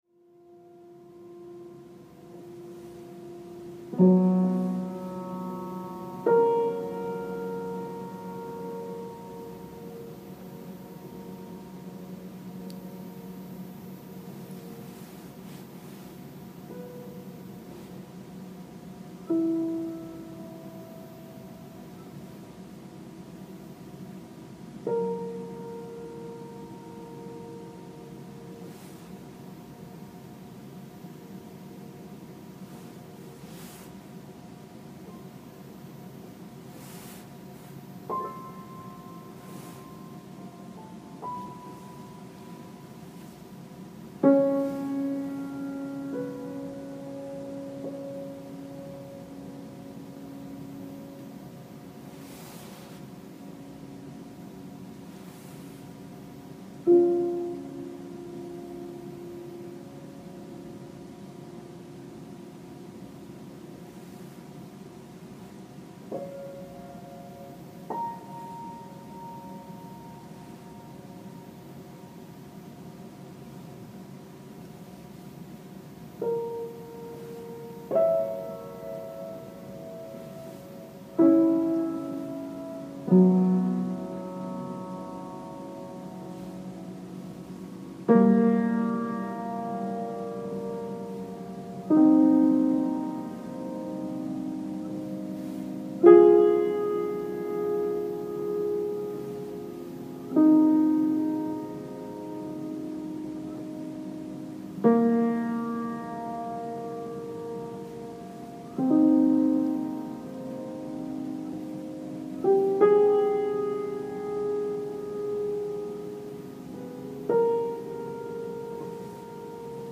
Rockville Early Morning Recordings (Audio 42)